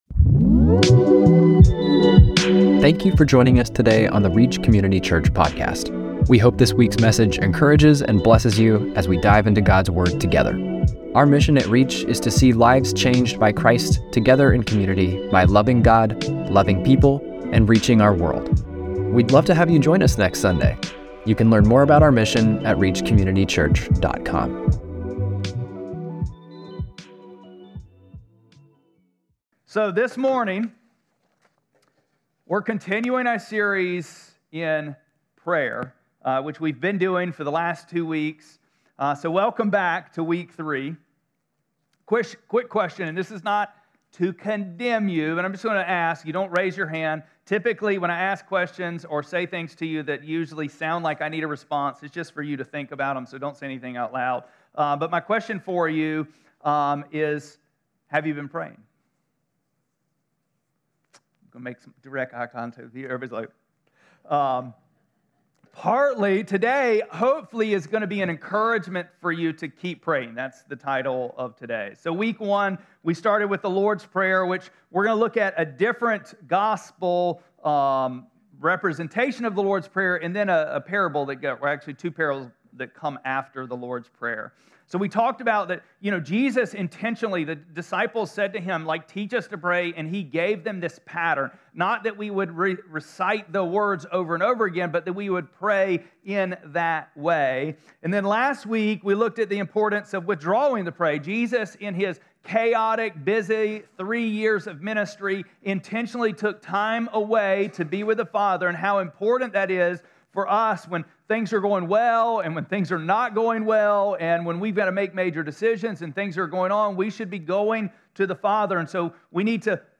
1-19-25-Sermon.mp3